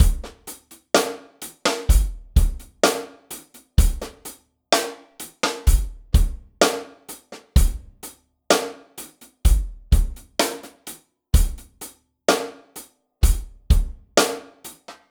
Track 10 - Drum Break 02.wav